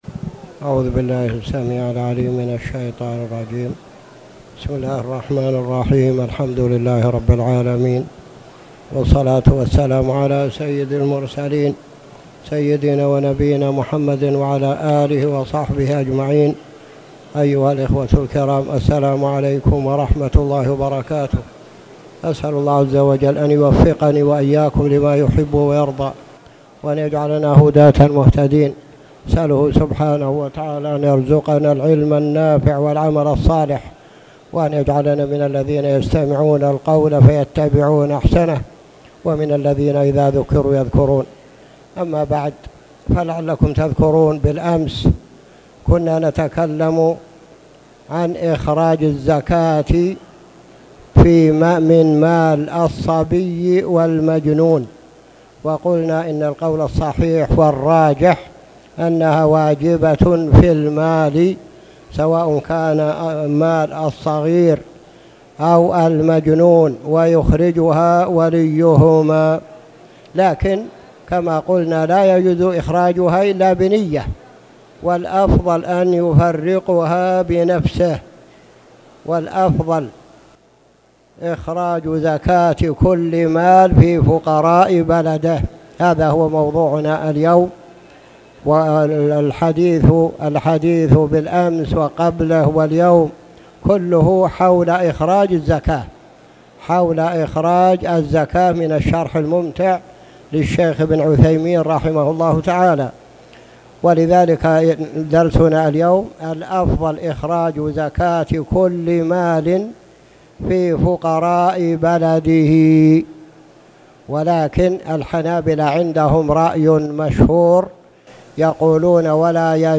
تاريخ النشر ٧ رجب ١٤٣٨ هـ المكان: المسجد الحرام الشيخ